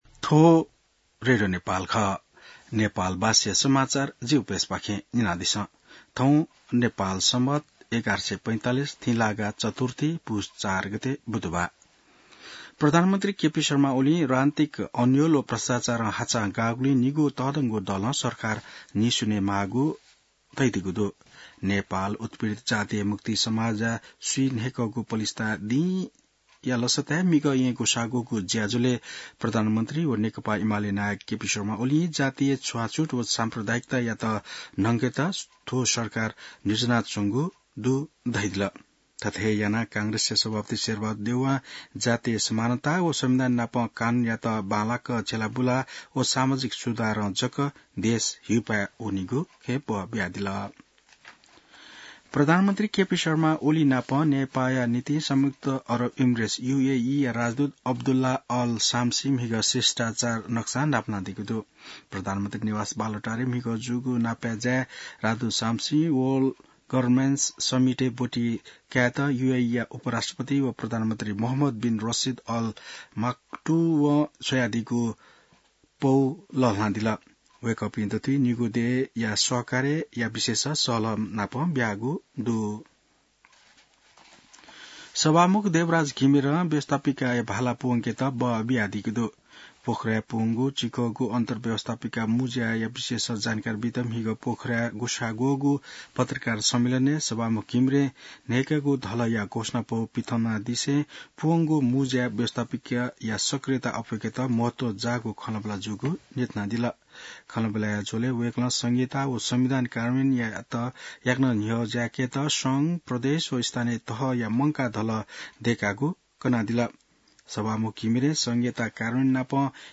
नेपाल भाषामा समाचार : ५ पुष , २०८१